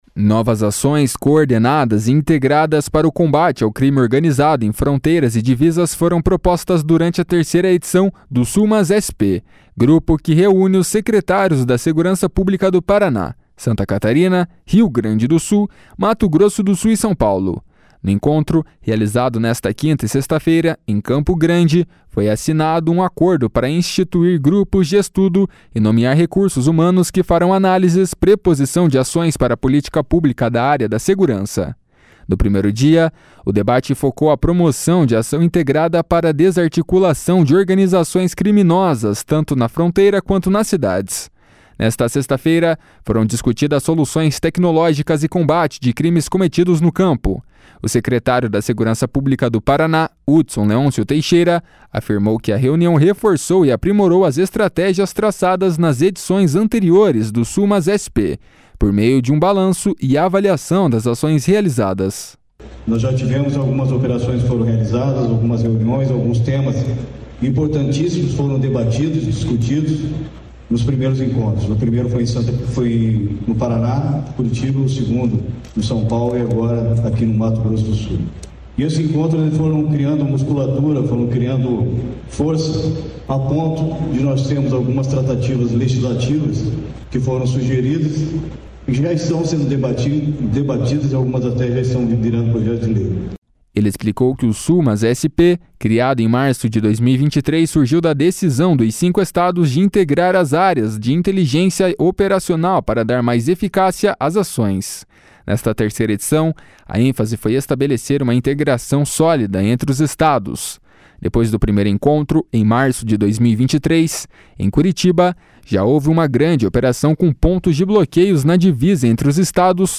O secretário da Segurança Pública do Paraná, Hudson Leôncio Teixeira, afirmou que a reunião reforçou e aprimorou as estratégias traçadas nas edições anteriores do SulMaSSP, por meio de um balanço e avaliação das ações realizadas. // SONORA HUDSON LEONCIO //